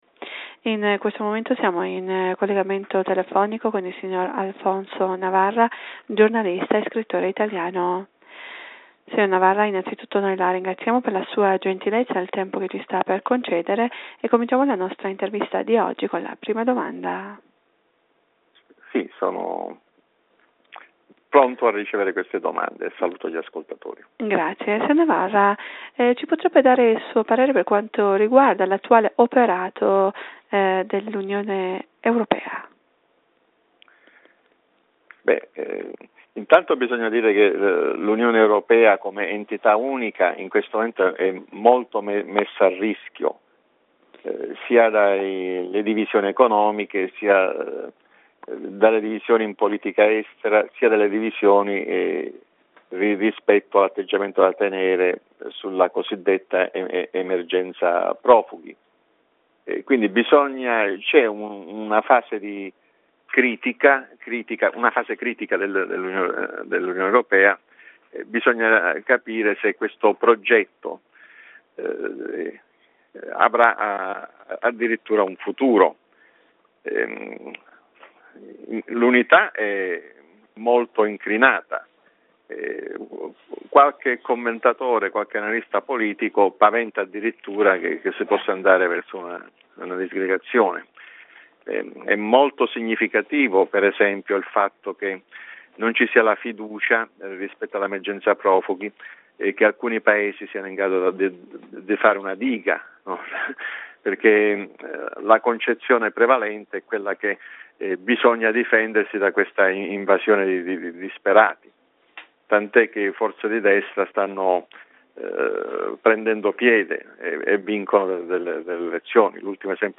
in un'intervista telefonica alla Radio Italia dell'Iran.